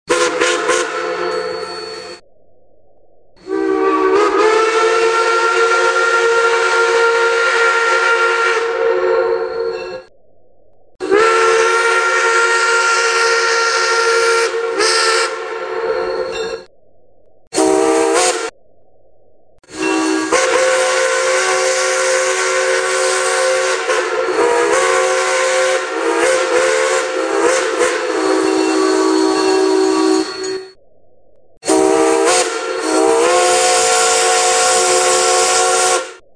Recorded Whistles for Live Steam Locomotives
Crosby 3 Chime
whistles_crosby_3_chime.mp3